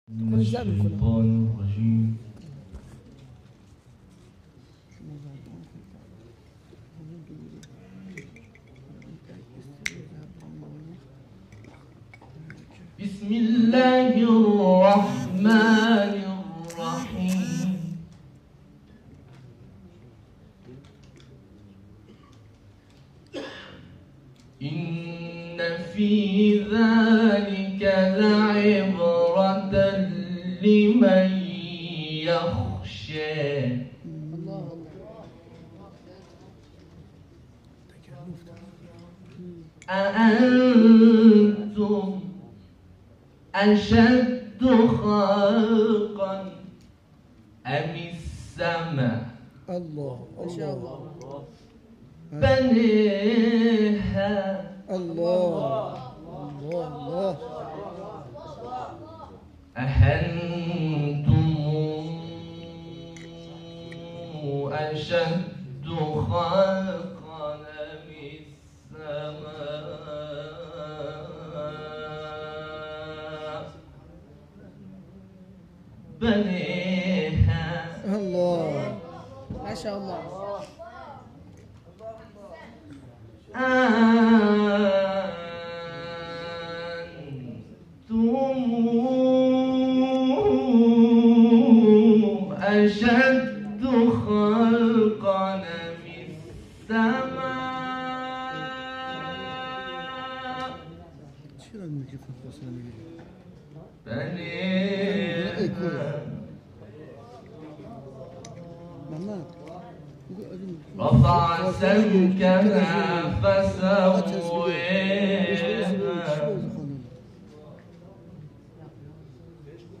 تلاوت نخبگان قرآن آموزشگاه صراط در مسابقات شوق تلاوت
گروه شبکه اجتماعی: فایل صوتی تلاوت کاروان اعزامی نخبگان آموزشگاه قرآنی صراط به دومین دوره مسابقات شوق تلاوت کشور در کربلای معلی ارائه می‌شود.